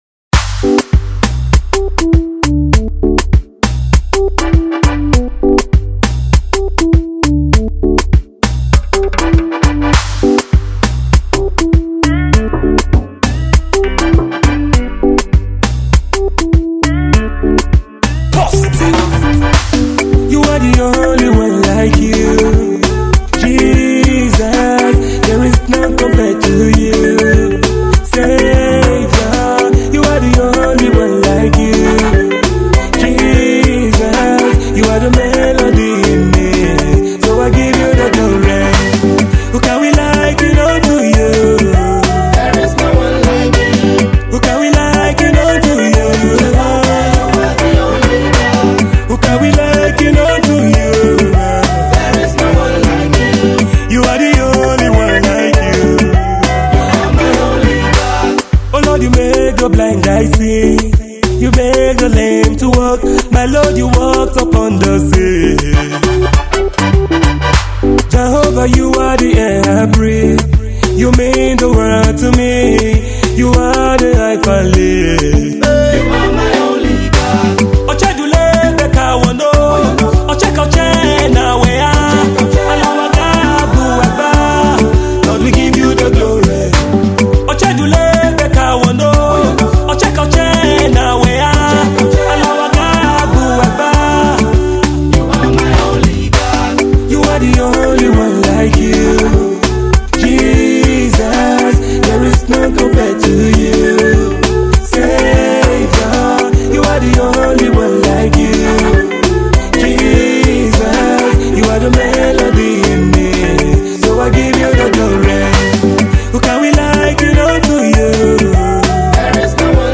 is a fast praise song that will get you dancing